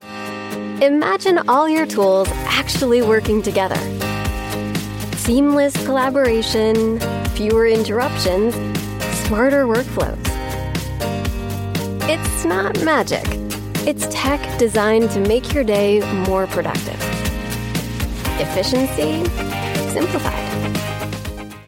Commercieel, Jong, Warm, Natuurlijk, Vriendelijk
Explainer
Leesstijlen variëren van super vrolijk en opgewekt tot kalmerend en meditatief tot droog en sarcastisch.